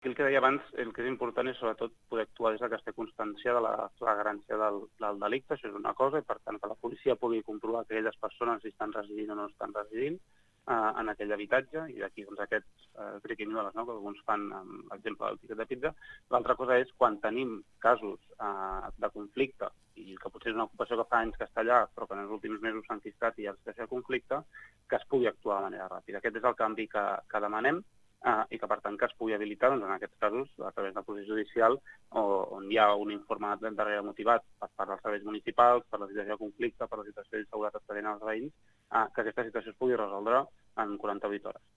en una entrevista al programa 'El Matí a Ràdio Estel'